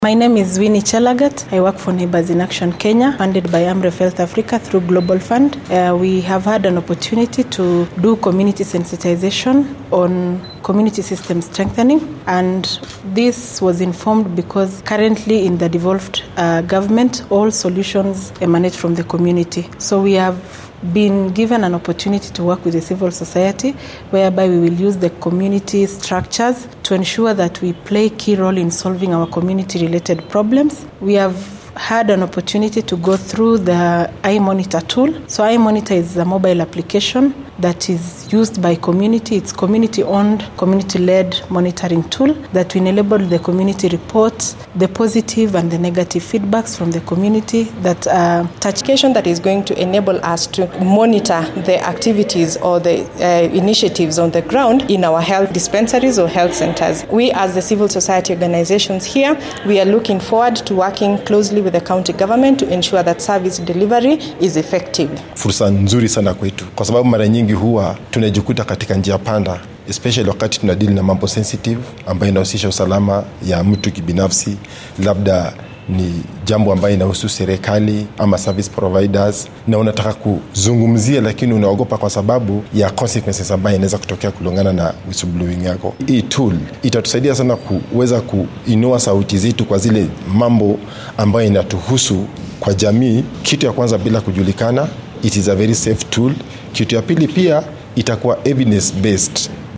NIAK-SOUND-BITE-ON-IMONITORING.mp3